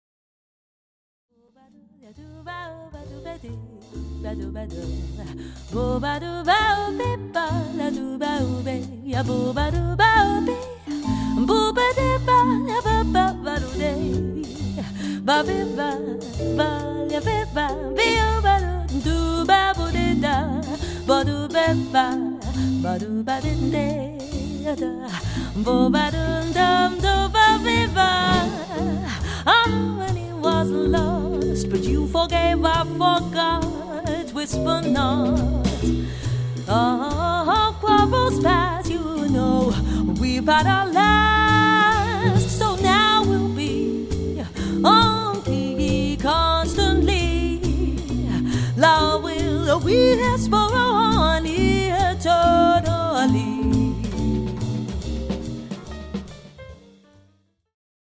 The Best In British Jazz
Recorded at Red Gables Studios, Nov 2008